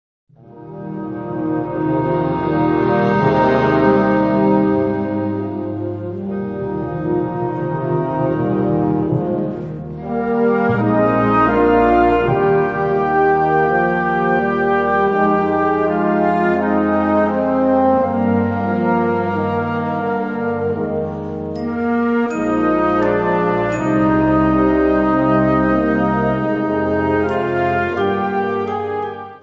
Volksweise
2:10 Minuten Besetzung: Blasorchester Tonprobe